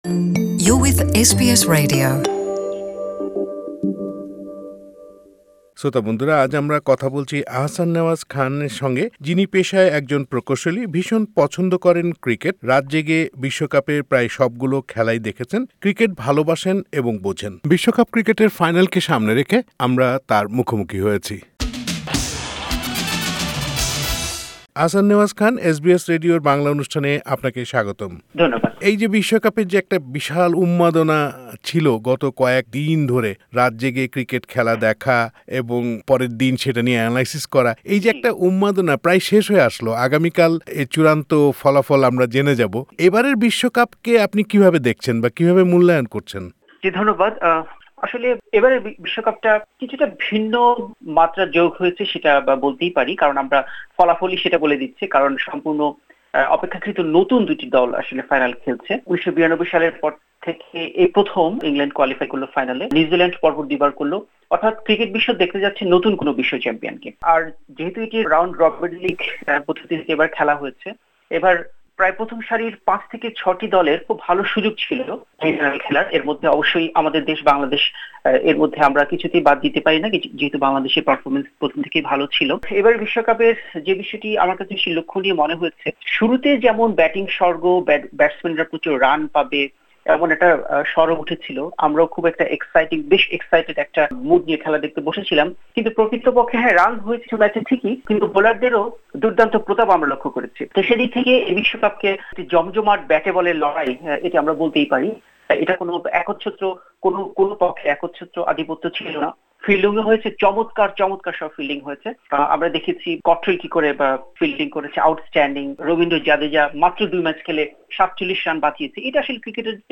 বিশ্বকাপ ক্রিকেট সম্পর্কে এসবিএস বাংলার সঙ্গে কথা বলেন তিনি। তার মতে, এবারের বিশ্বকাপে জমজমাট ব্যাটে-বলে লড়াই ছিল।